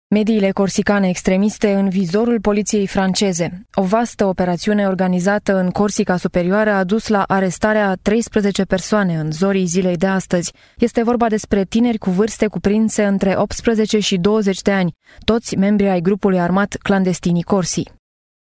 rumänische Profi Sprecherin für Werbung, TV, Industrie, Radio etc. Professional female voice over talent from Romania
Sprechprobe: Industrie (Muttersprache):
Professional female voice over talent romanian